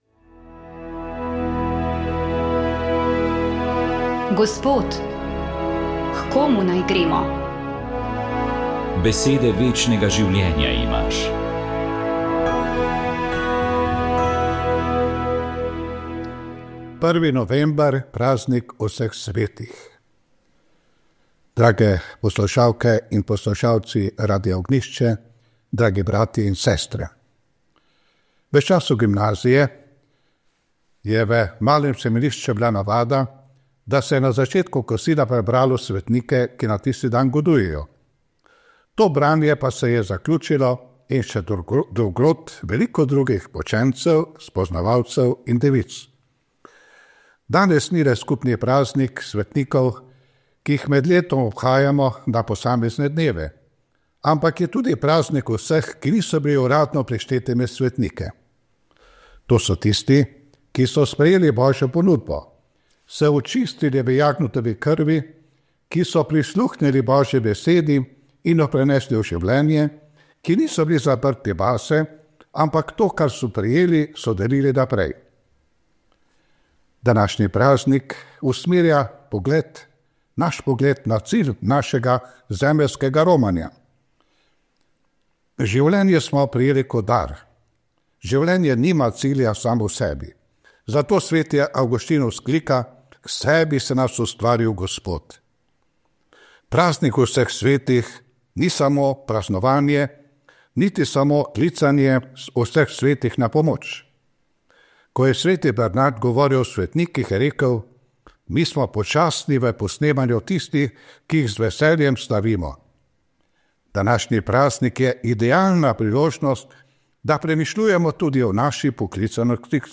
Molitev je vodil nadškof Stanislav Zore.